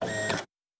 pgs/Assets/Audio/Sci-Fi Sounds/Mechanical/Servo Small 3_2.wav at 7452e70b8c5ad2f7daae623e1a952eb18c9caab4
Servo Small 3_2.wav